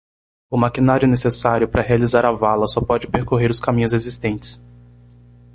Uitgesproken als (IPA)
/peʁ.koˈʁe(ʁ)/